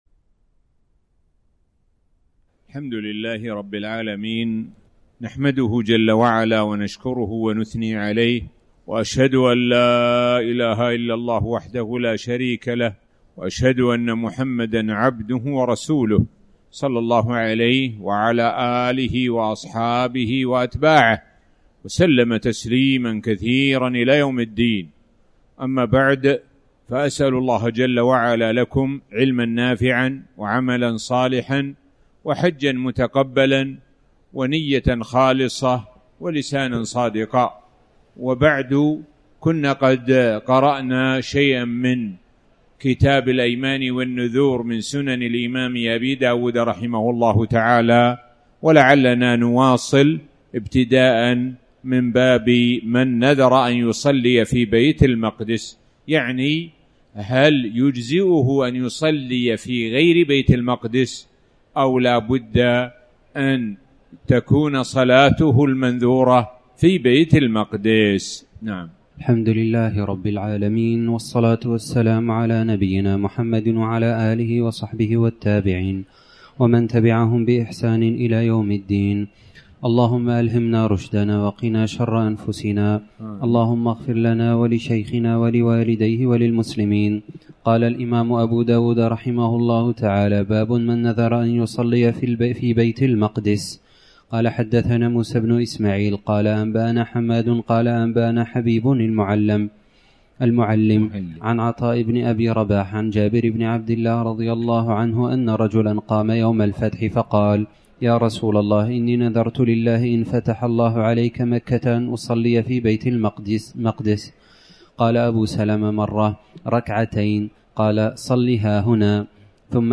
تاريخ النشر ٢٦ ذو القعدة ١٤٣٩ هـ المكان: المسجد الحرام الشيخ: معالي الشيخ د. سعد بن ناصر الشثري معالي الشيخ د. سعد بن ناصر الشثري باب من نذر ان يصلي في بيت المقدس